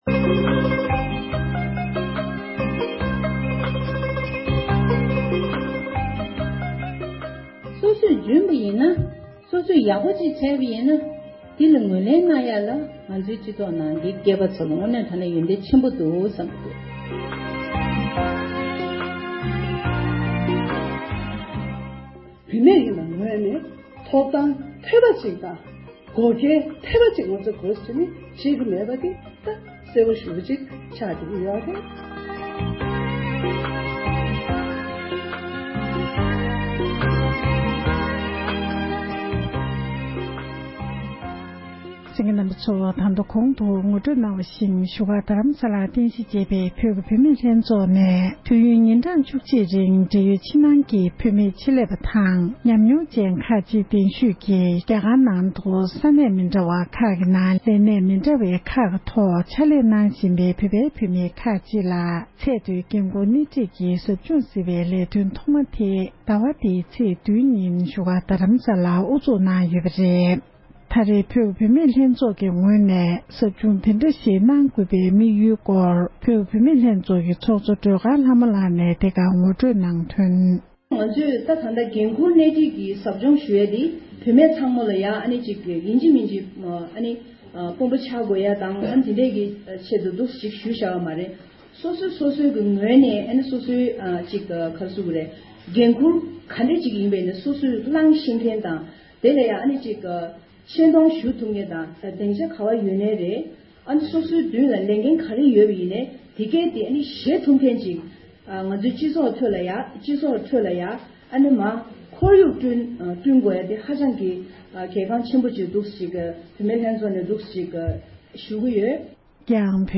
འབྲེལ་ཡོད་མི་སྣར་བཅའ་འདྲི་ཞུས་ཏེ་གནས་ཚུལ་ཕྱོགས་བསྒྲིགས་ཞུས་པར་གསན་རོགས༎